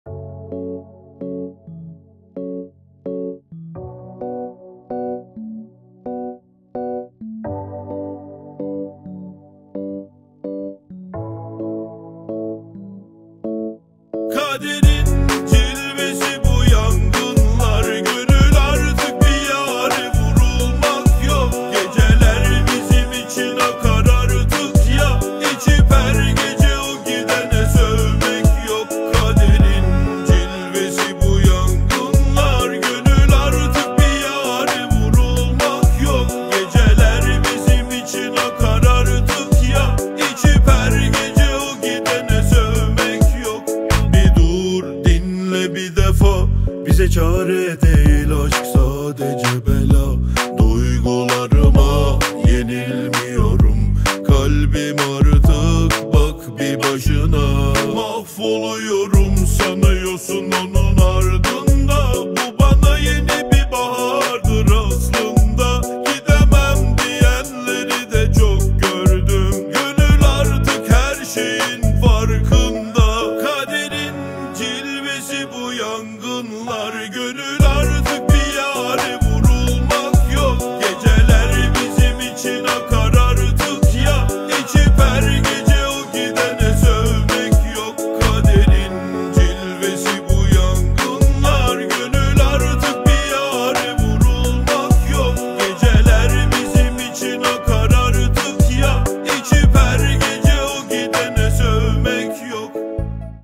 Жанр: Турецкие